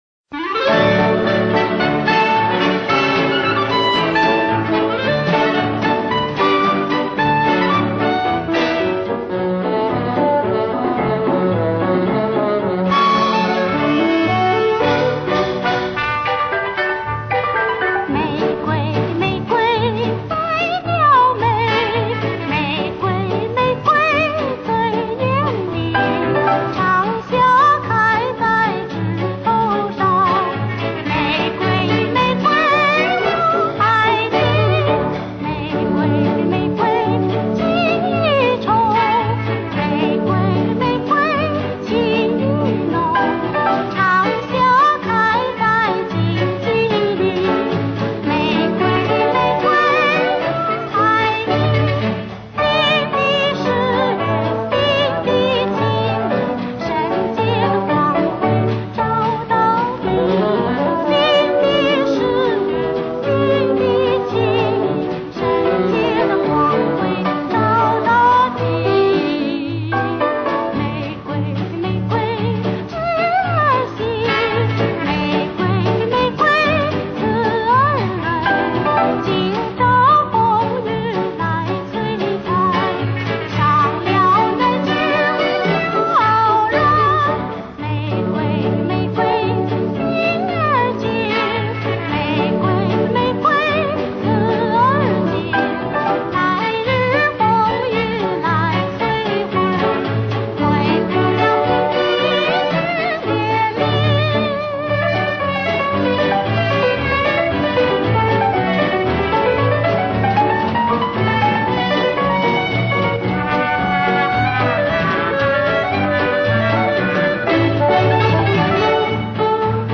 但后来变得稳健醇厚